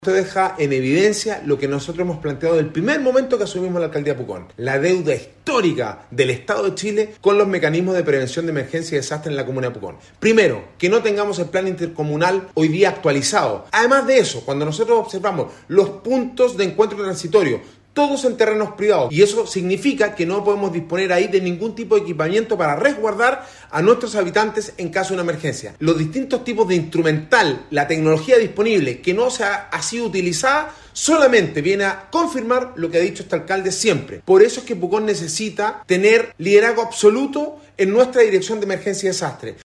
Alcalde-Sebastian-Alvarez-confirma-deficit-detectados-por-Contraloria-en-el-OVDAS-.mp3